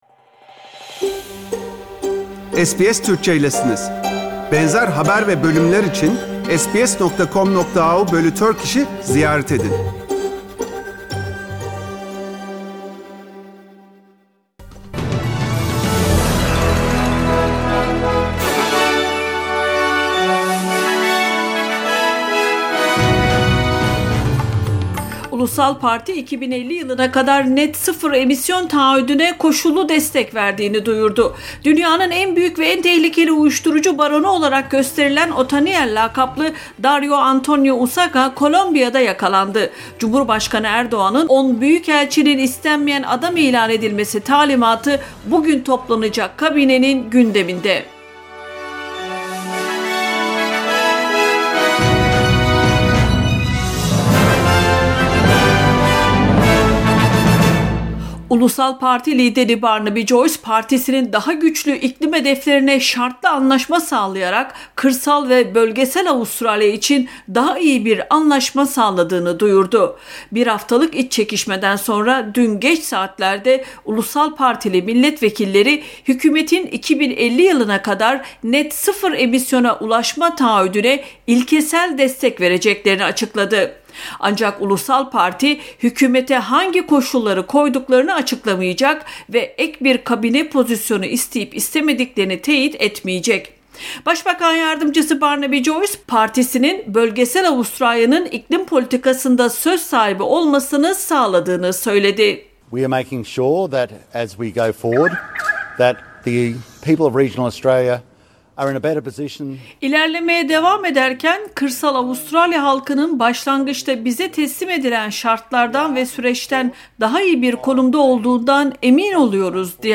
SBS Türkçe Haberler 25 Ekim